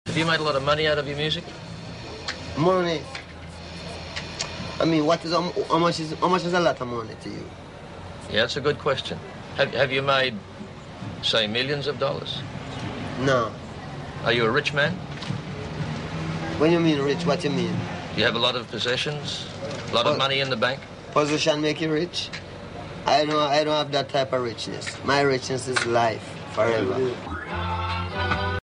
As we approach the premiere of the Bob marley fim set to release on 14th of February its only right to remember some of his best interview moments.